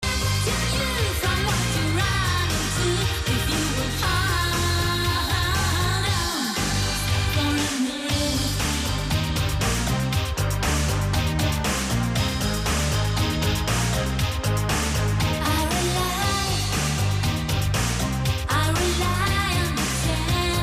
FM-тюнер
Запись в mp3 ограничена системными параметрами 22 кГц 56 Кбит/с. Традиционно это ограничение обходится заменой стандартного кодека от Microsoft на полную версию Fraunhofer MPEG Layer-3 codec или использованием альтернативных вариантов.
Тюнер продемонстрировал хорошее качество звука (
• Хорошее качество звука в режиме FM-радио